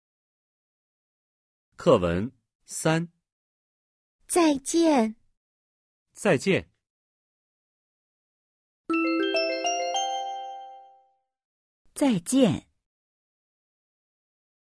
(3) Hội thoại 3